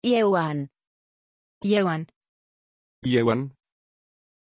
Pronunciación de Ieuan
pronunciation-ieuan-es.mp3